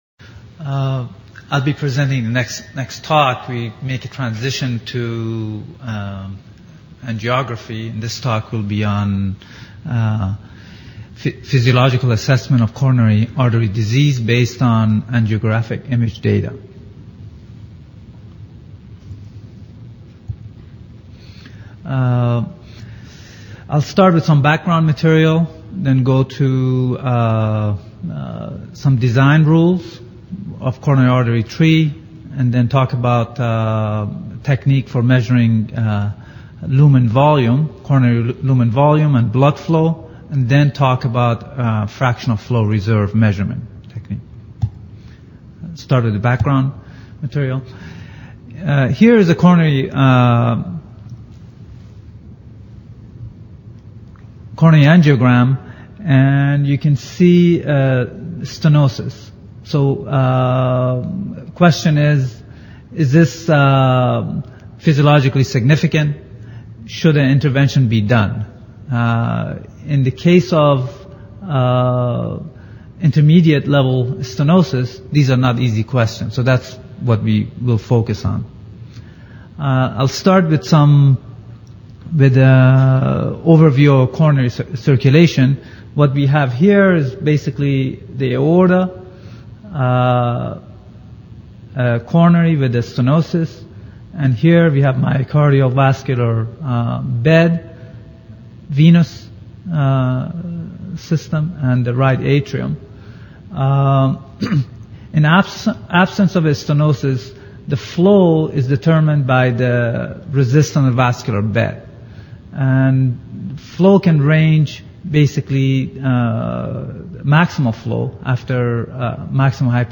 50th AAPM Annual Meeting - Session: Advances in Cardiovascular Imaging